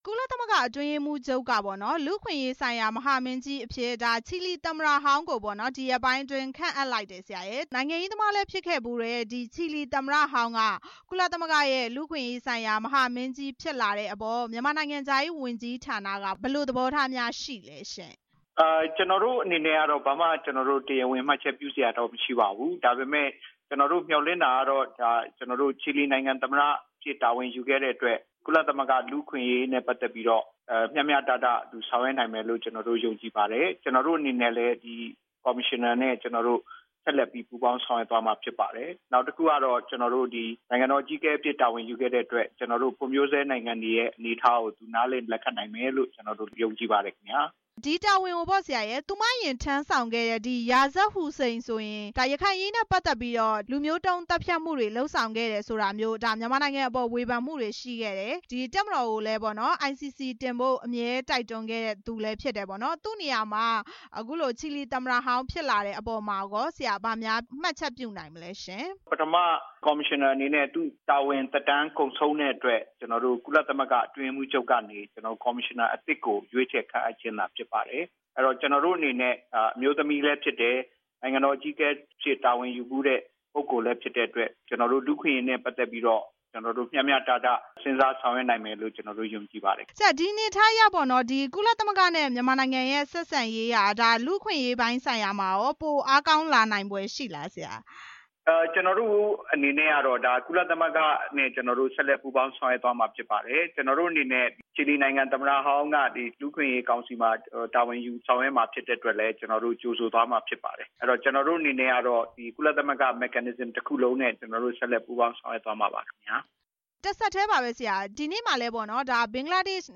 နိုင်ငံခြားရေးဝန်ကြီးဌာန အမြဲတမ်းအတွင်းဝန် ဦးမြင့်သူနဲ့ ဆက်သွယ်မေးမြန်းချက်